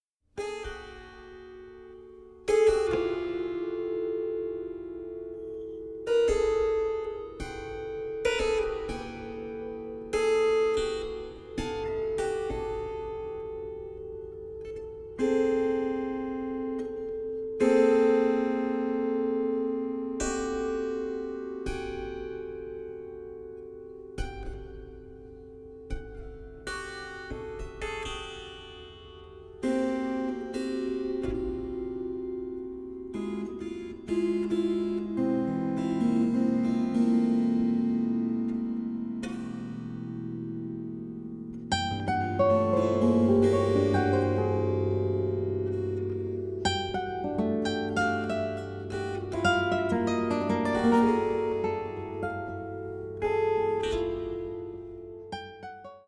16-string classical guitar, 16-string Contraguitar